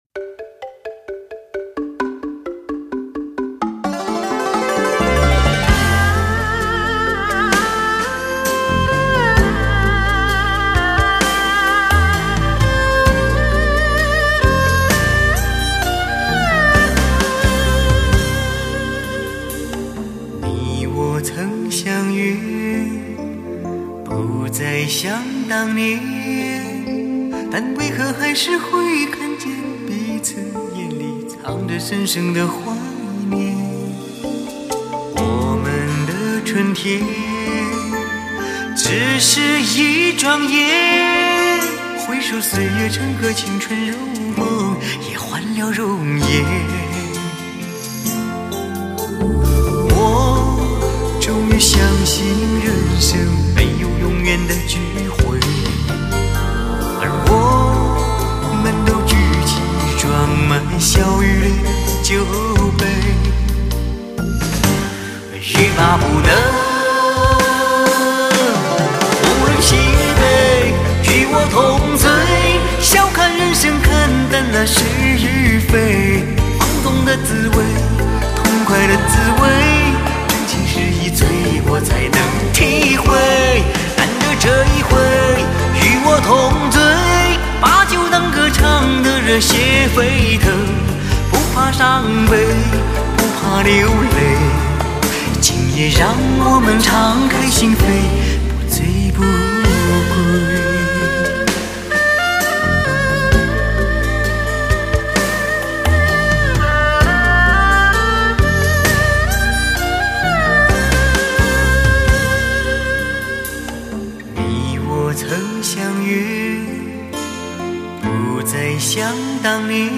类型: 天籁人声